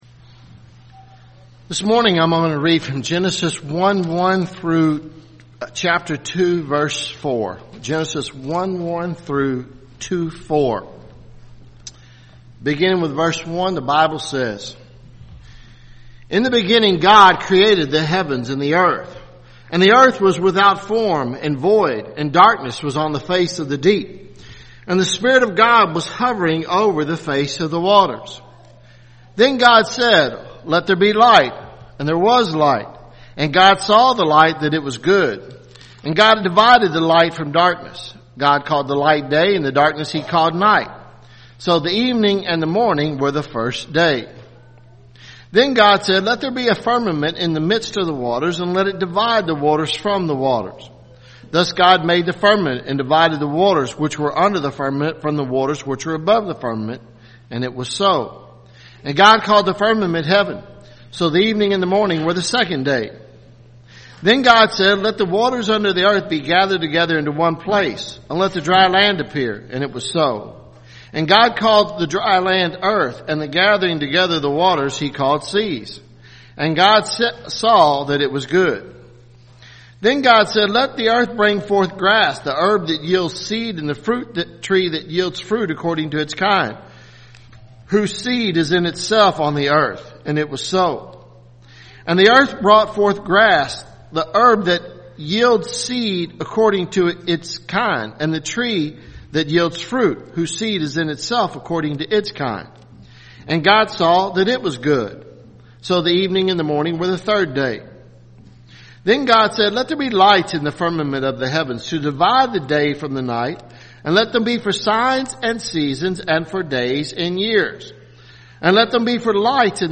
Sermons Jan 01 2017 “Thoughts on Time